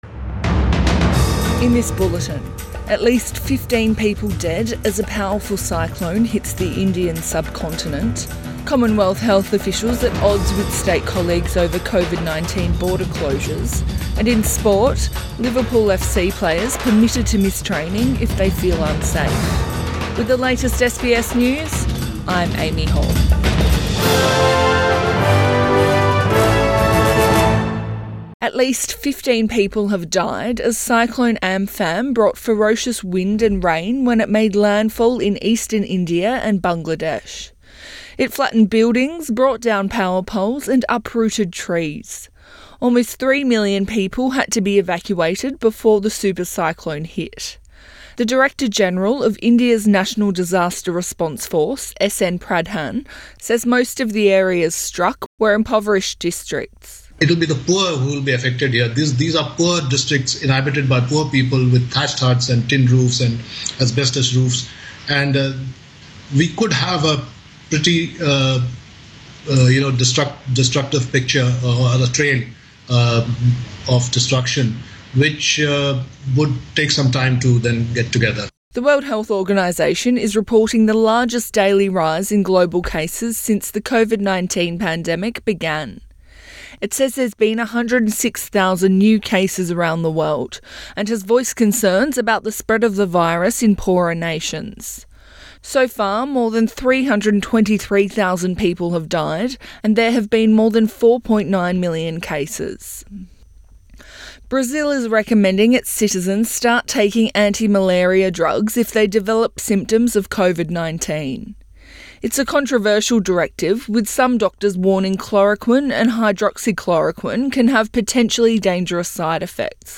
AM bulletin 21 May 2020